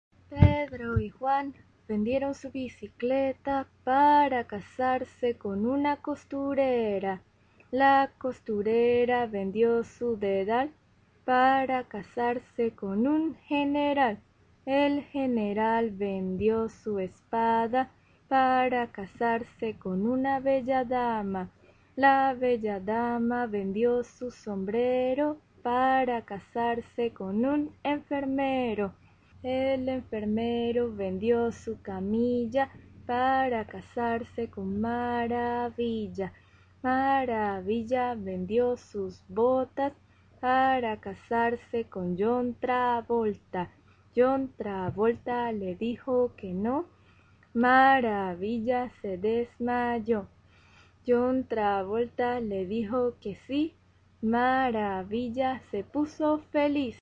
Sólo una canción infantil con gestos de manos…
(8) "enfermero": siempre se dice en la canción con las manos sobre la boca como una máscara de hospital.